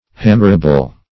Meaning of hammerable. hammerable synonyms, pronunciation, spelling and more from Free Dictionary.
Search Result for " hammerable" : The Collaborative International Dictionary of English v.0.48: Hammerable \Ham"mer*a*ble\ (-[.a]*b'l), a. Capable of being/formed or shapeo by a hammer.